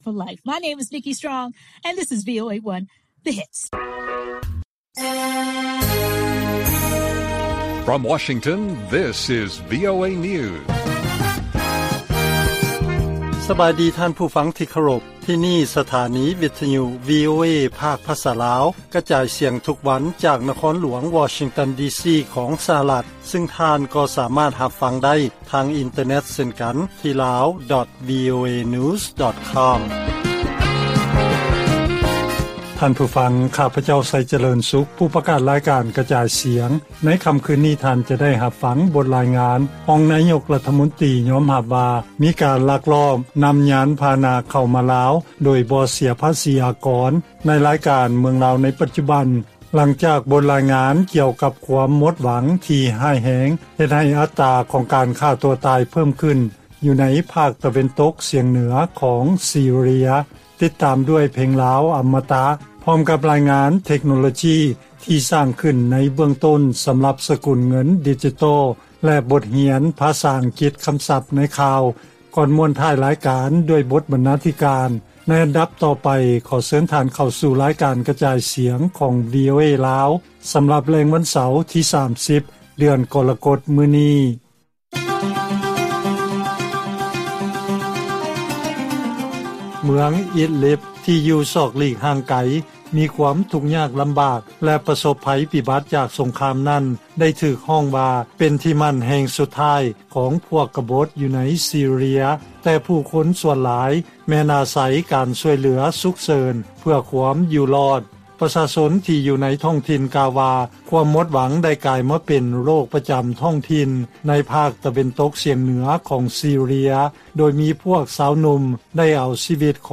ລາຍການກະຈາຍສຽງ ຂອງວີໂອເອລາວ: ລາວຍອມຮັບວ່າ ມີການລັກລອບນຳເຂົ້າຍານພາຫະນະແບບບໍ່ເສຍພາສີ